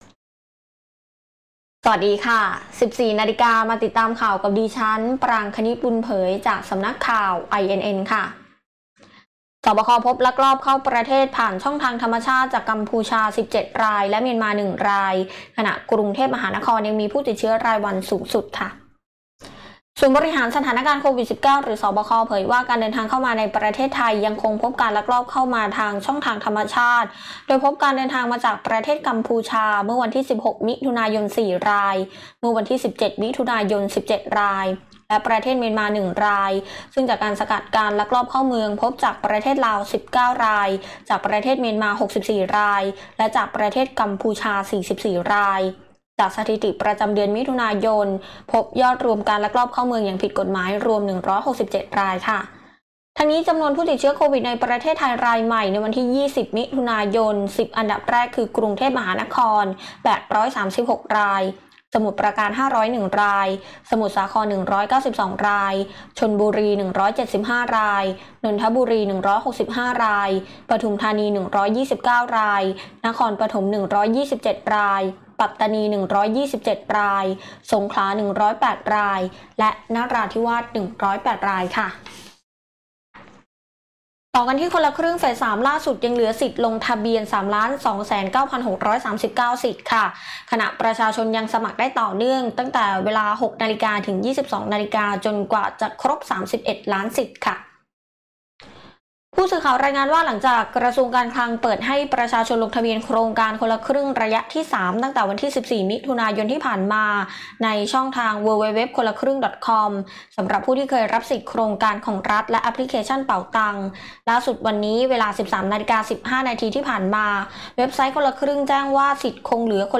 ข่าวต้นชั่วโมง 14.00 น.